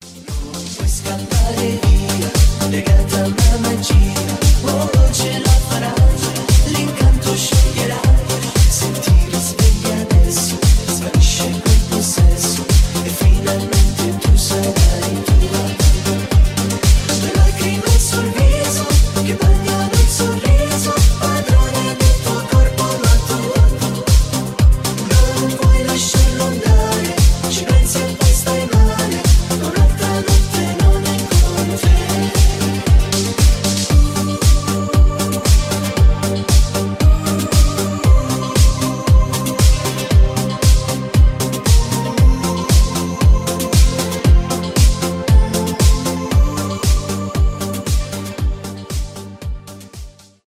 танцевальные , итало диско